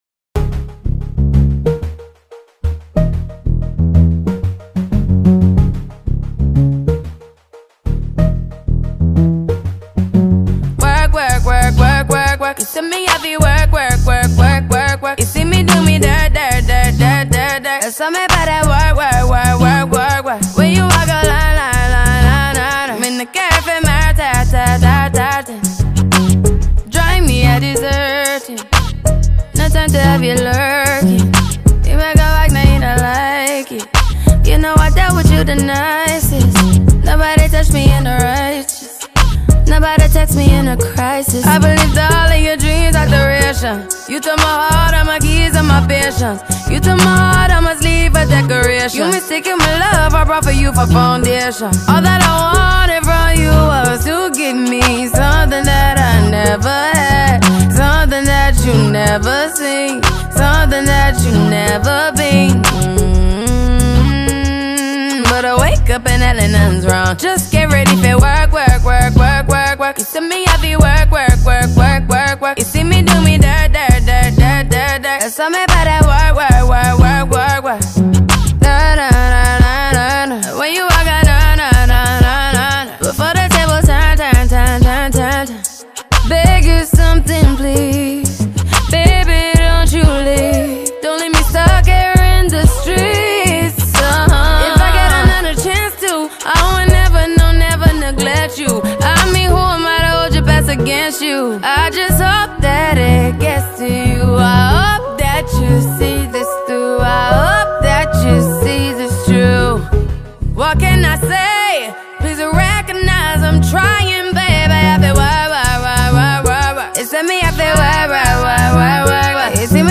R & B
• Genre: electronic
• Subgenre: Dance-pop